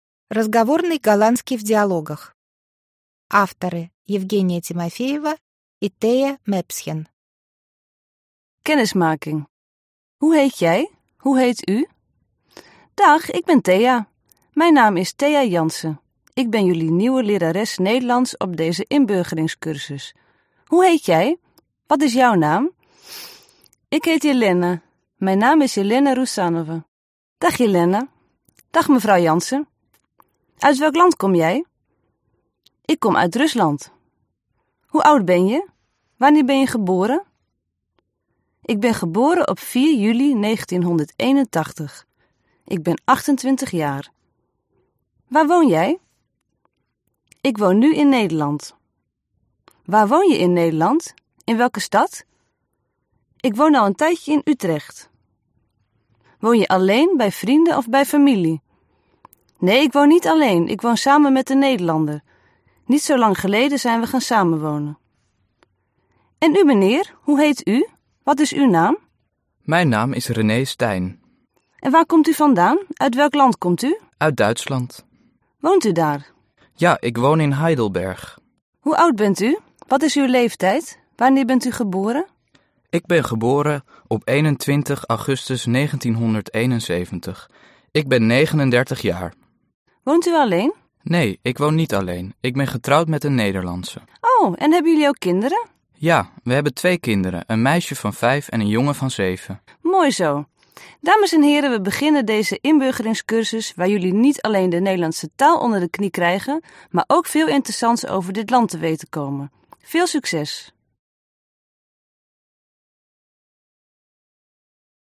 Аудиокнига Разговорный нидерландский в диалогах. Аудиоприложение | Библиотека аудиокниг